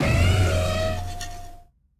Cri de Fort-Ivoire dans Pokémon Écarlate et Violet.